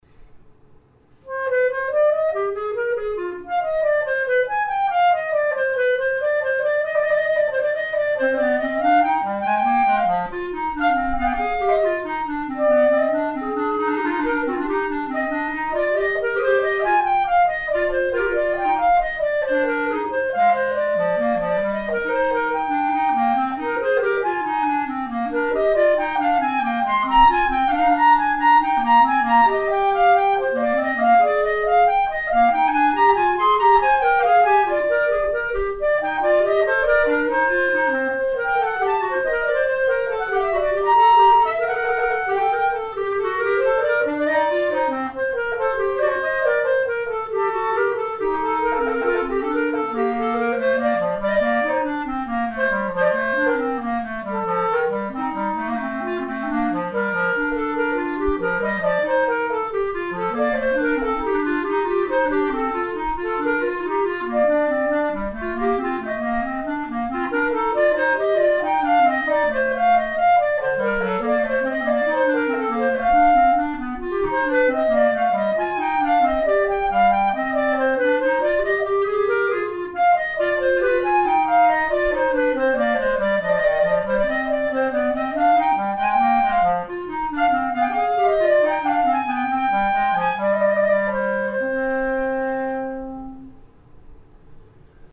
ひとり二重奏
二重奏を、普通に多重録音をするだけなのだが、相手が見えないだけで、ちょっとした入りなどが合わない。
見事なまでに、走ったり遅れたり。メトロノーム聞きながら吹いてるのに、、、＞＜